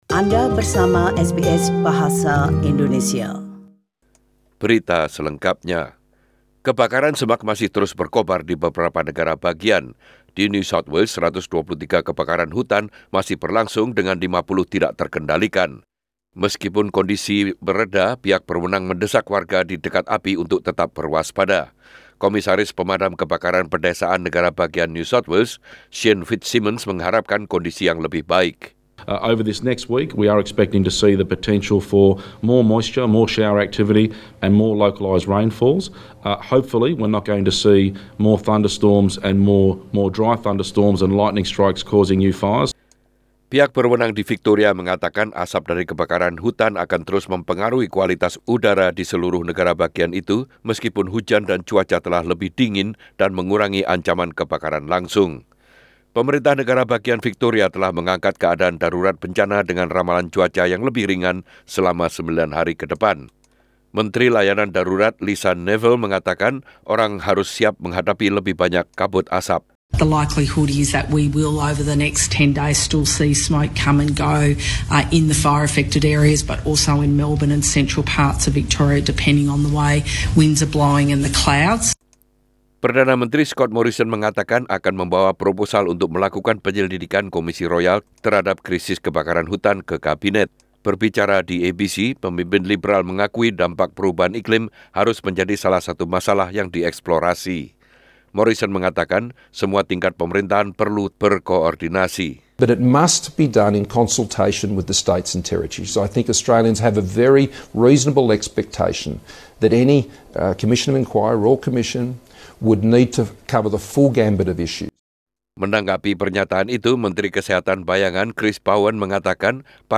SBS Radio News in Indonesian - 12 Jan 2020